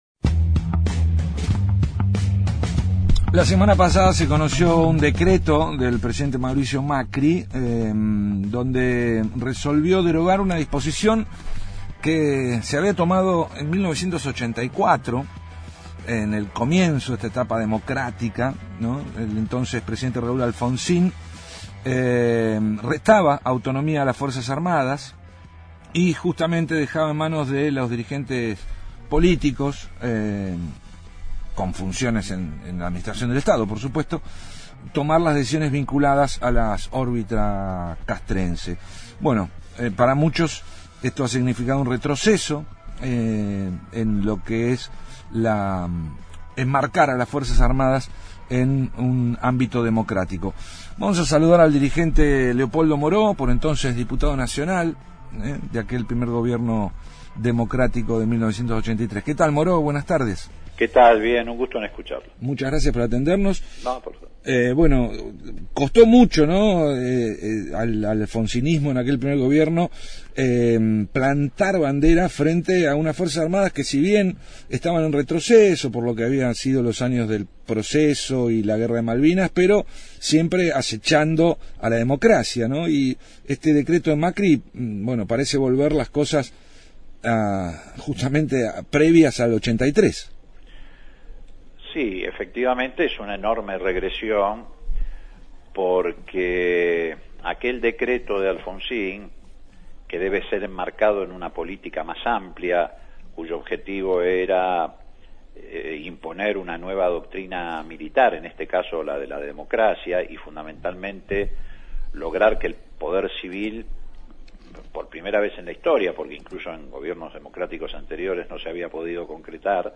Mauricio Macri resolvió a través de un decreto derogar la disposición tomada por el entonces presidente Raúl Alfonsín y mantenida por todos los gobiernos hasta la fecha, que restaba autonomía a los militares y dejaba bajo la responsabilidad de los políticos elegidos democráticamente, las decisiones vinculadas con la órbita castrense. Conversamos con Leopoldo Moreau, ex diputado nacional y referente del radicalismo.